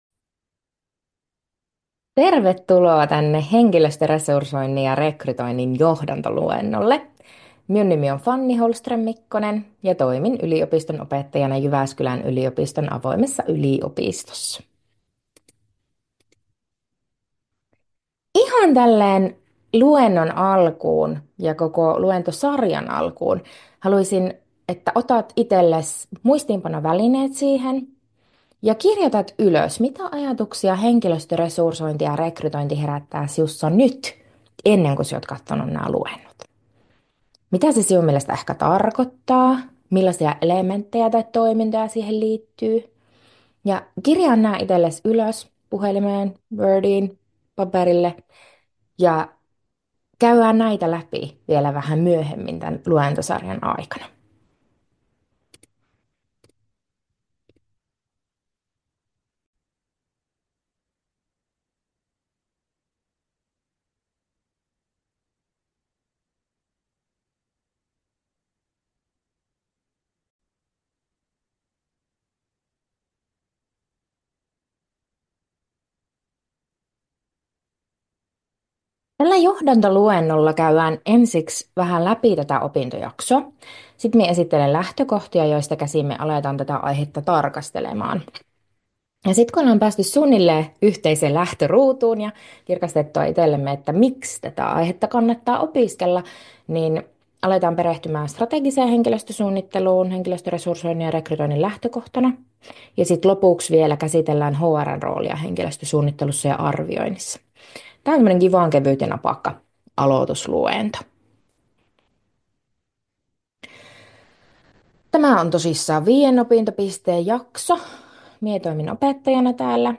YJOA2220 Henkilöstöresursointi ja rekrytointi, johdantoluennon tallenne.